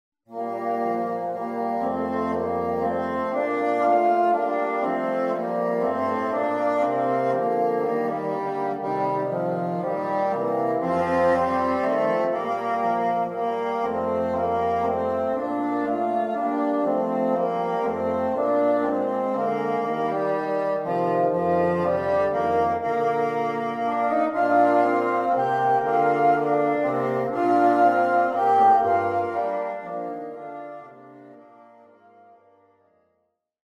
Bassoon Trio
Three bassoon trios based on Scottish Folk Songs.